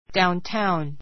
downtown A2 dauntáun ダウン タ ウン 名詞 形容詞 商店街（の）, 繁華 はんか 街（の）, 町の中心地区（の） 参考 都市の中心部で, デパート・銀行・商店などが集まっている商業地区をいう.